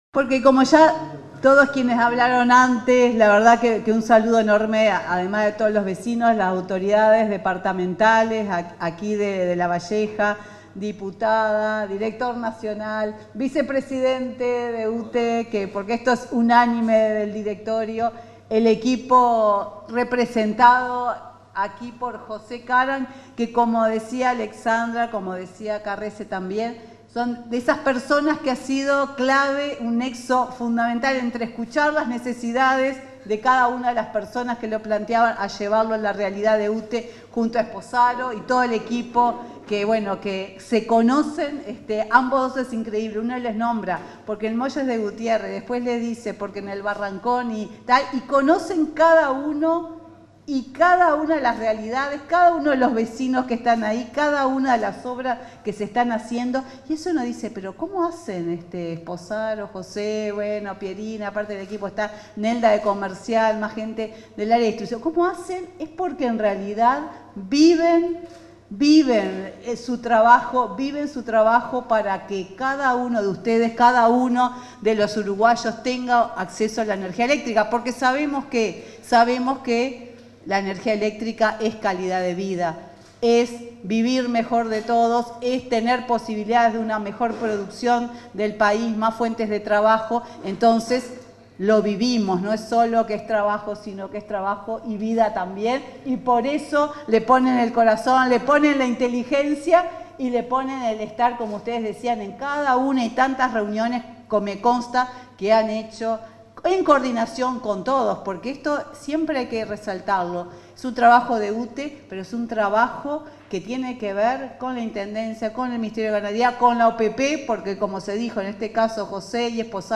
Palabras de la presidenta de UTE, Silvia Emaldi
Palabras de la presidenta de UTE, Silvia Emaldi 11/12/2024 Compartir Facebook X Copiar enlace WhatsApp LinkedIn En el marco de la inauguración de obras de electrificación rural en los departamentos de Lavalleja y Florida, este 11 de diciembre, se expresó la presidenta de la UTE, Silvia Emaldi.